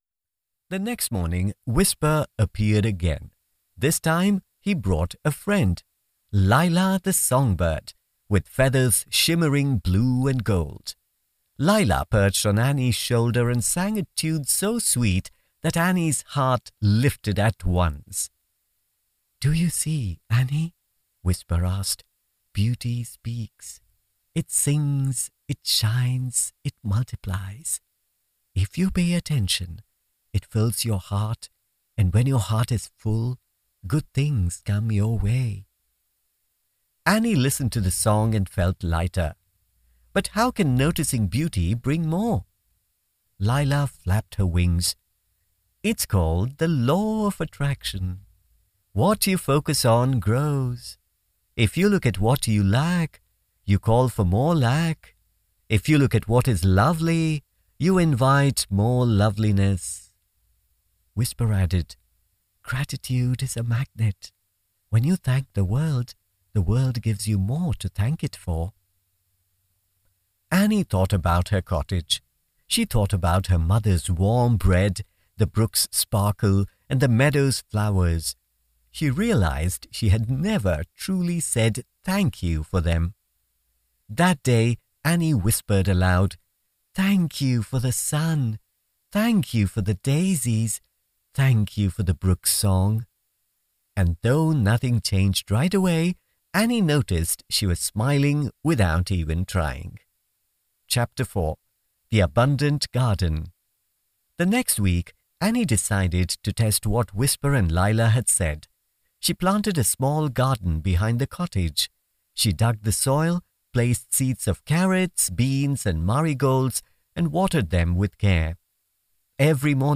Male
Warm, friendly, soothing voice with a good balance of bass and clear high end.
Middle-aged Neutral English accent tilted towards British/Indian with clear enunciation, and judicious pace.
Audiobooks
Children'S Story
1202AudiobookChildren.mp3